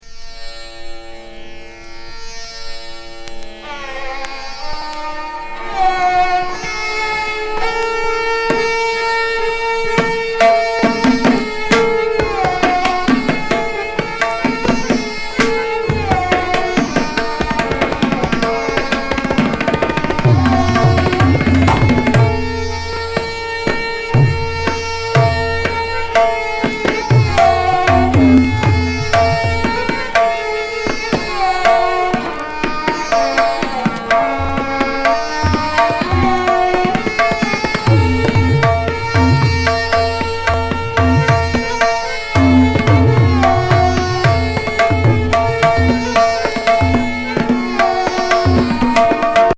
伝説のタブラ・マスターの共演!妖しい弦楽器のような音に、魅惑的な